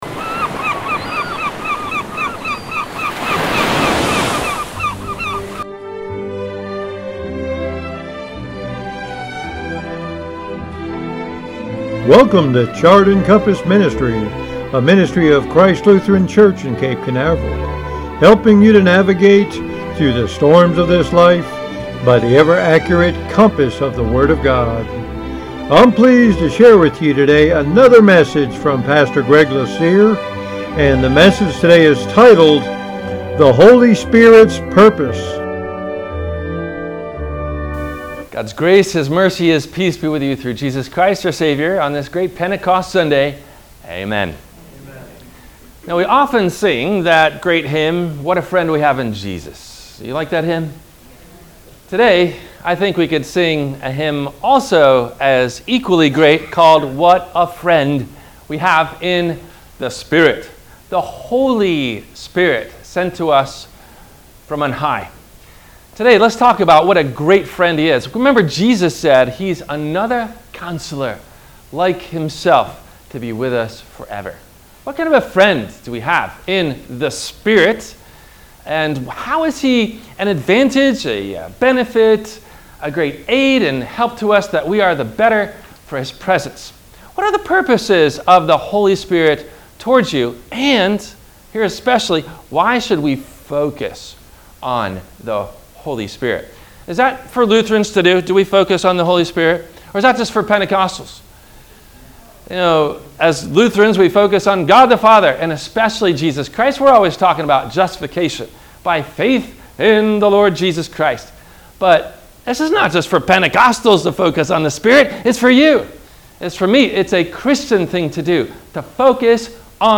The Holy Spirit’s Purpose – WMIE Radio Sermon – June 05 2023